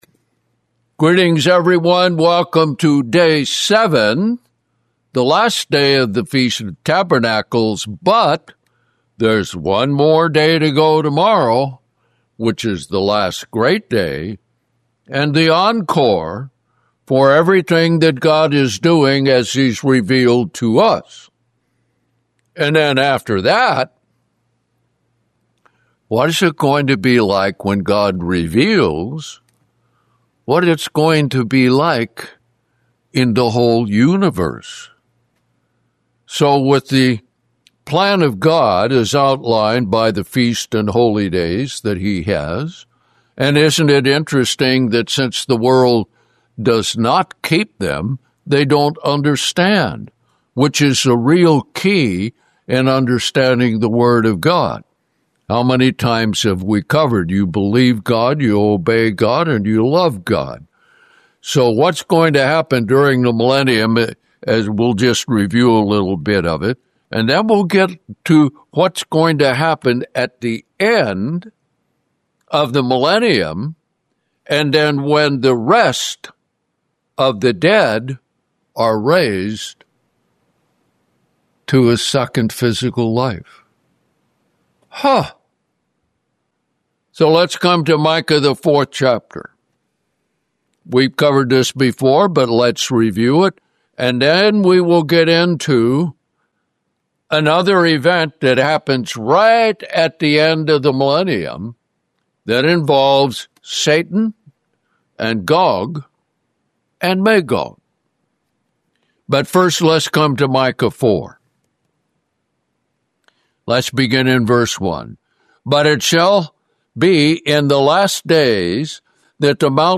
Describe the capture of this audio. (FOT Day 7)